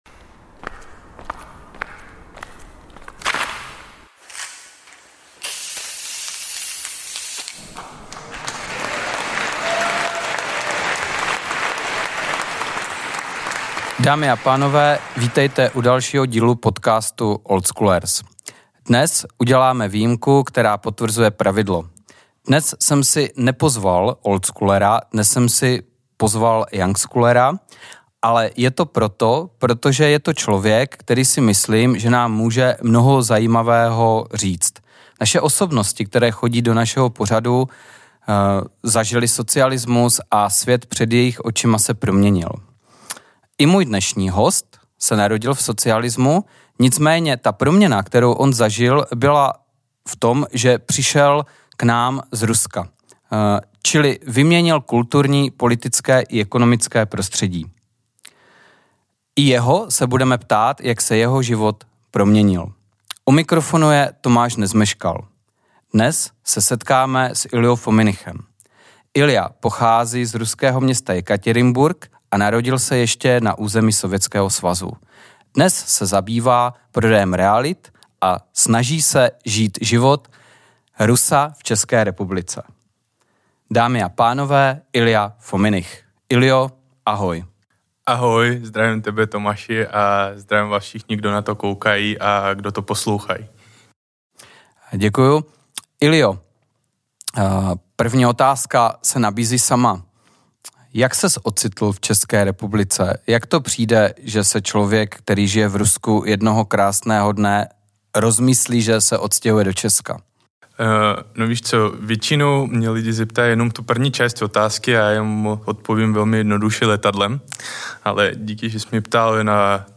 Je v našem formátu výjimkou, mladý Rus, který žije v Čechách. Ačkoli není oldschooler, jeho pohled na nás Čechy a život zde je natolik zajímavý, že jsme s ním natočili rozhovor.